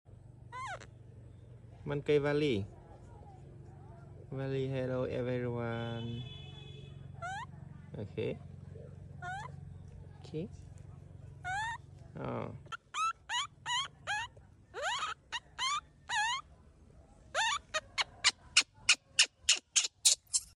baby monkey name VALI. hello sound effects free download